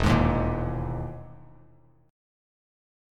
EM#11 chord